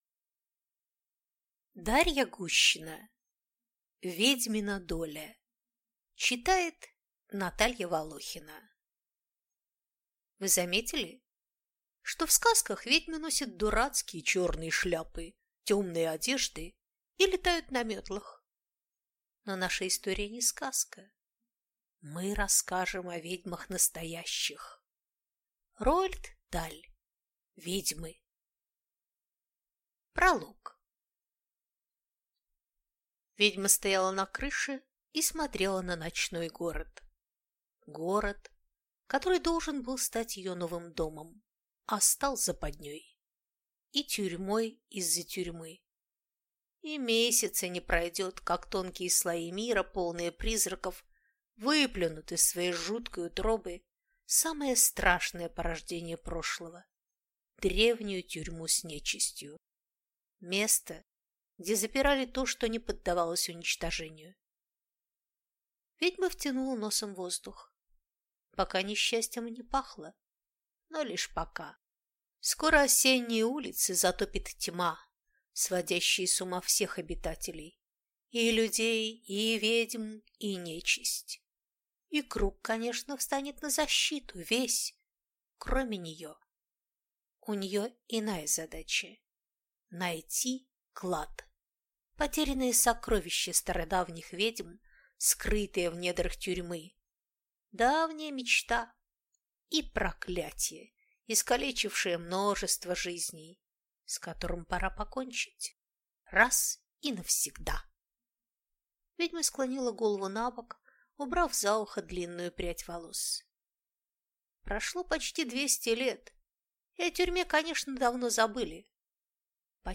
Аудиокнига Ведьмина доля | Библиотека аудиокниг